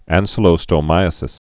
(ănsə-lō-stō-mīə-sĭs, ăngkə-lō-)